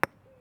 concrete2.wav